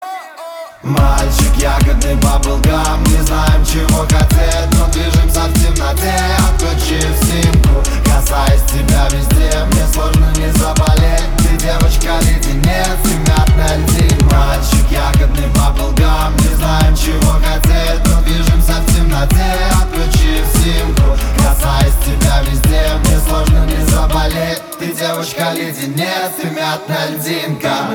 Танцевальные
поп
мужской вокал